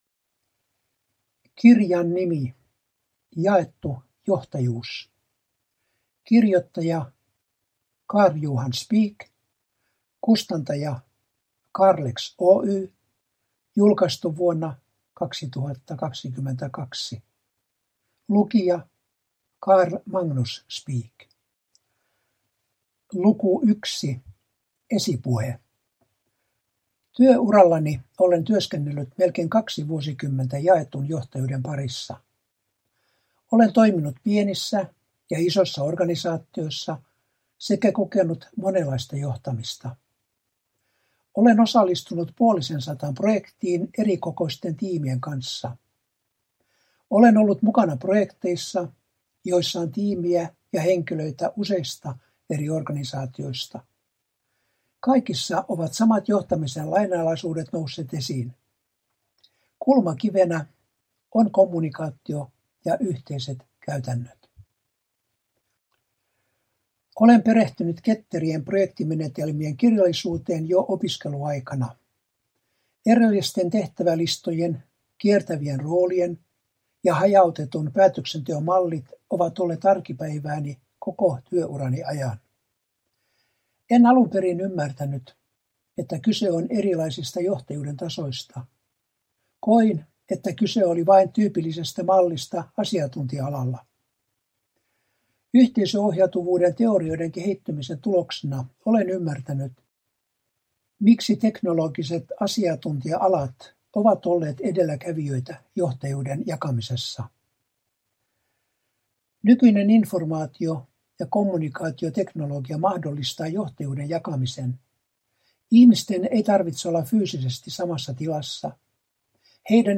Yhteisöohjautuvuus : Jaettu johtajuus – Ljudbok